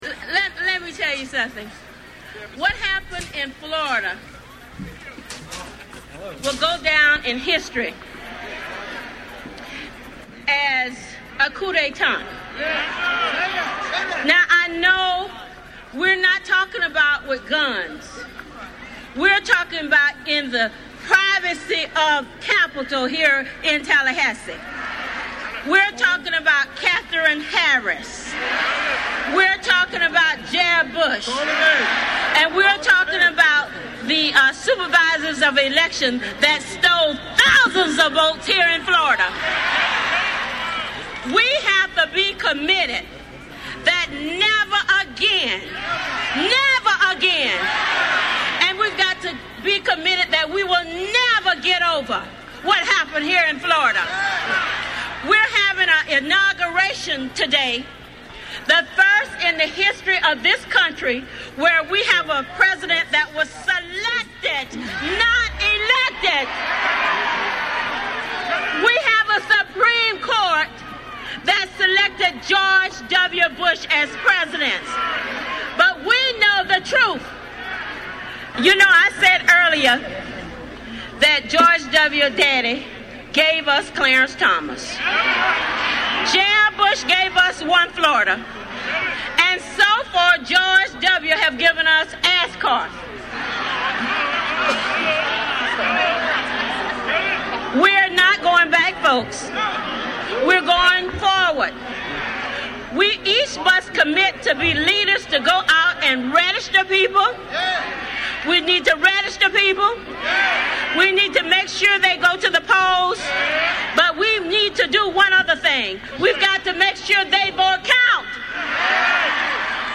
In a stirring speech at a counter-inaugural protest in Tallahassee, FL, Rep. Corrine Brown (D-FL) exhorts the crowd to never again let the President be selected rather than elected.